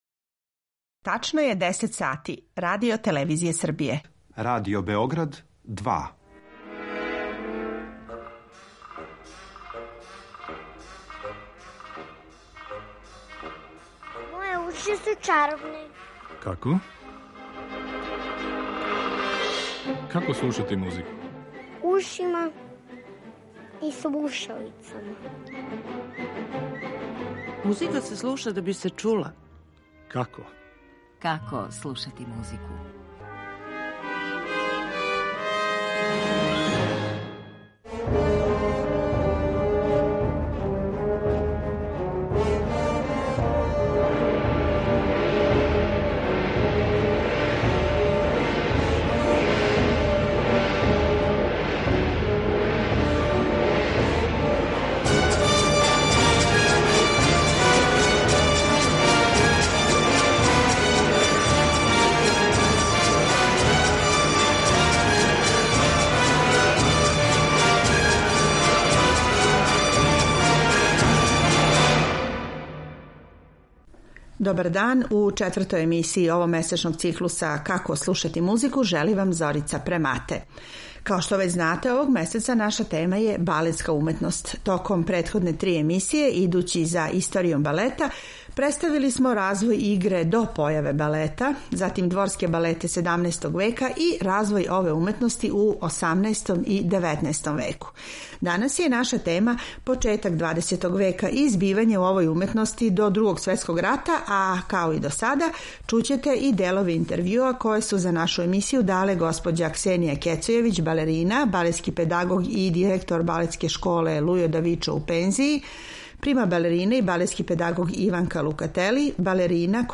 У емисији ћете имати прилику и да чујете одломке мање познатих, као и чувених балета који су обележили историју овог жанра у музици.